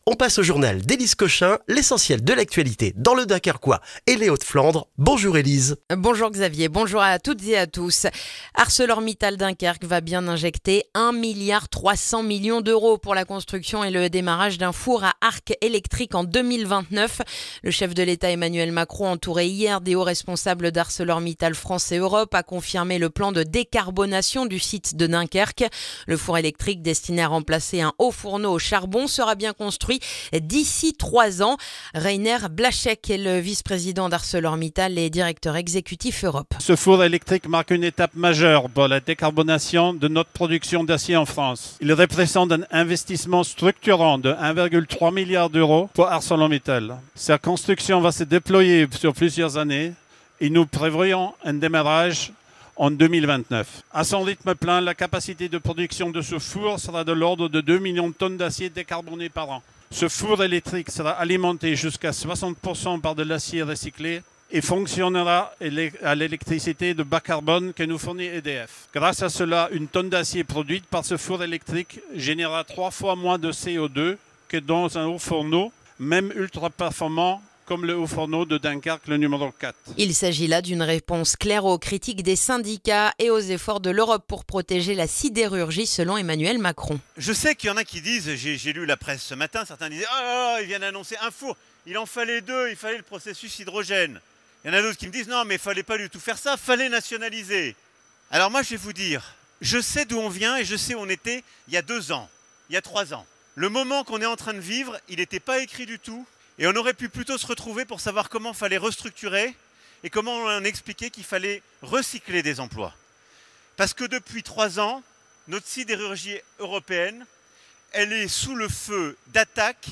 Le journal du mercredi 11 février dans le dunkerquois